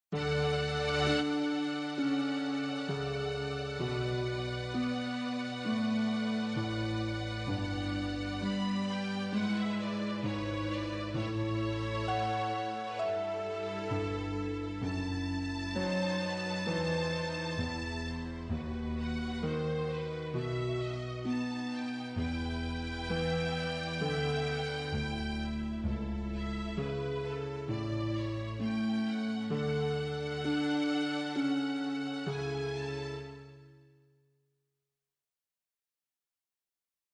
Includes harp and timpani.